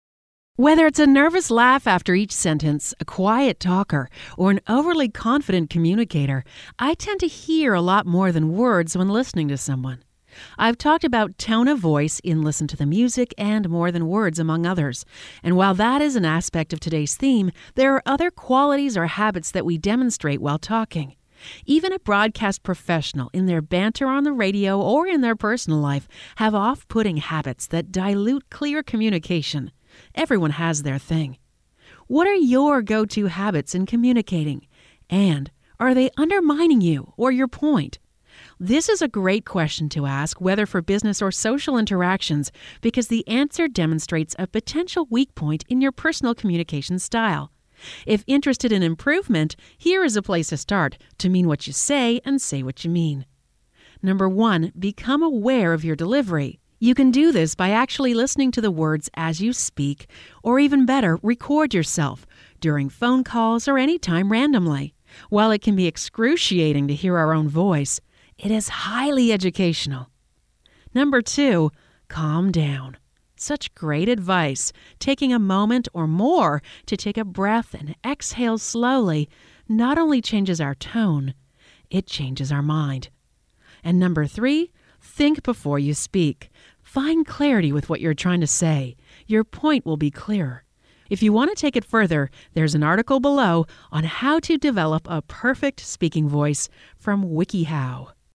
One Thing Leads to Another* (audio version of blog below) Whether it’s a nervous laugh after each sentence, a quiet talker, or an overly confident communicator, I tend to “hear” a lot more than words when listening to someone.